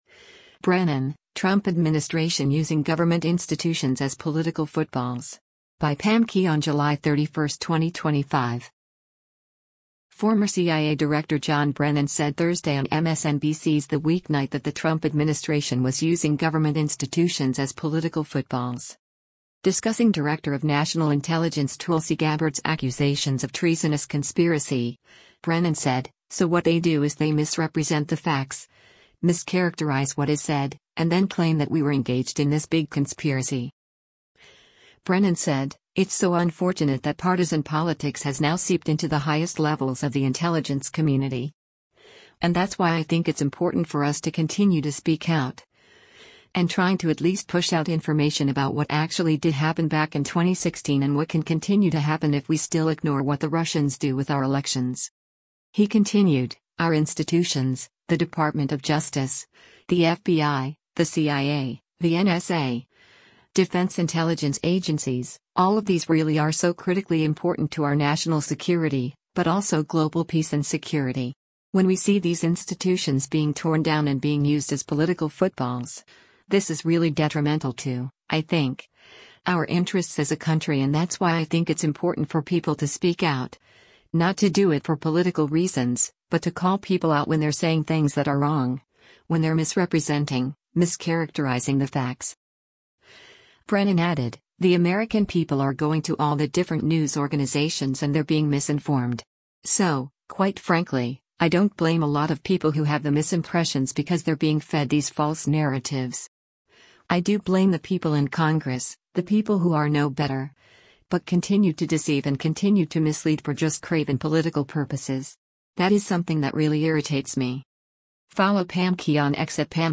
Former CIA Director John Brennan said Thursday on MSNBC’s “The Weeknight” that the Trump administration was using government institutions as “political footballs.”